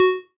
noteblock_bit.wav